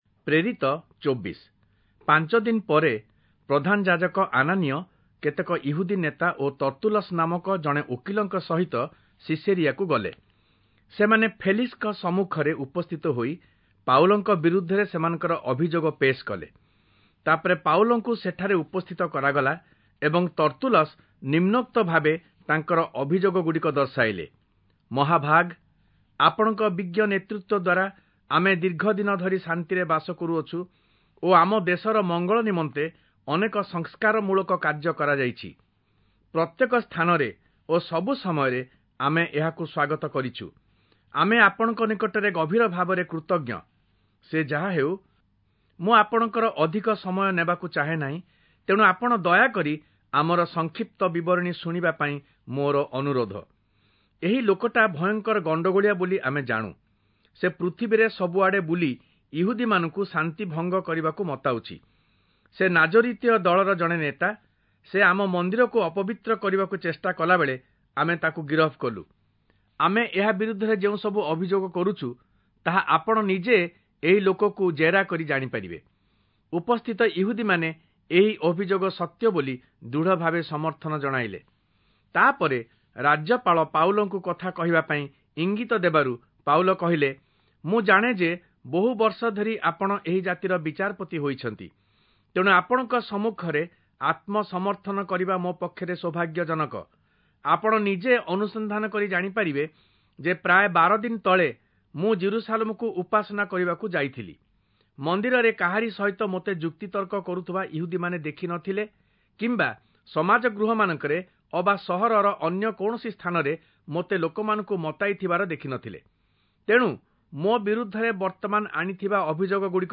Oriya Audio Bible - Acts 6 in Net bible version